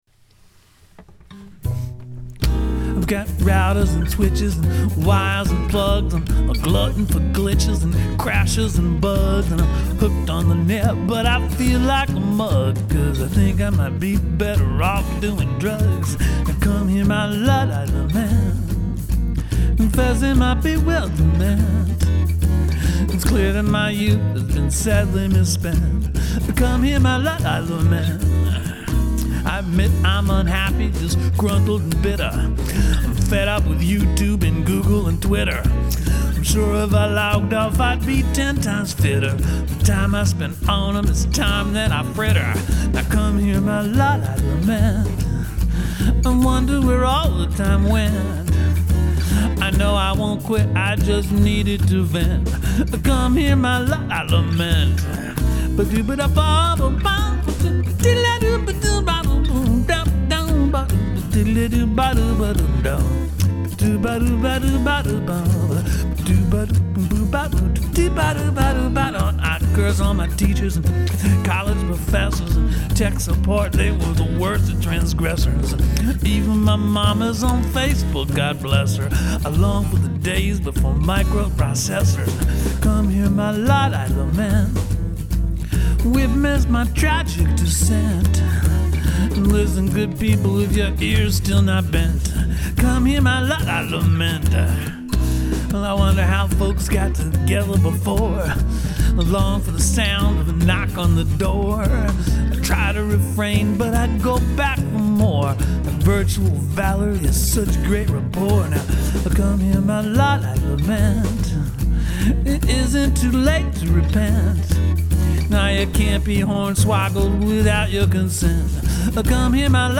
[ ruff basics - not for sale or distribution ]